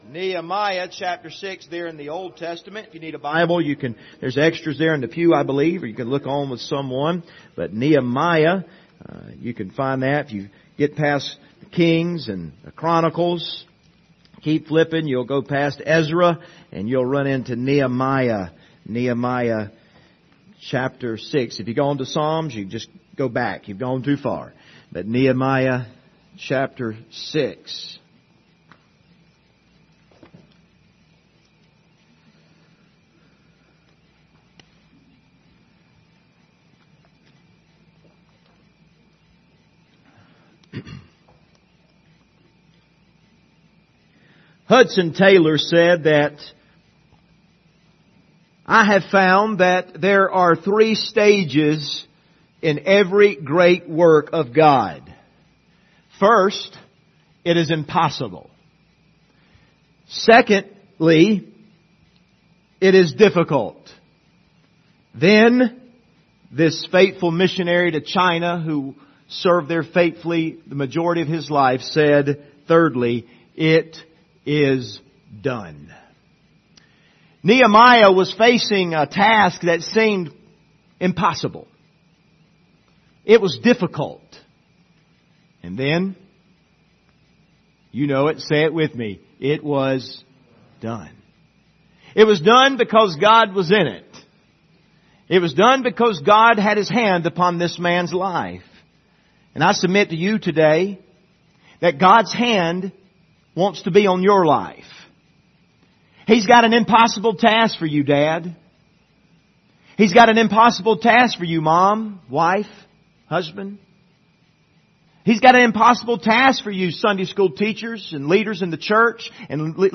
Passage: Nehemiah 6:1-19 Service Type: Sunday Morning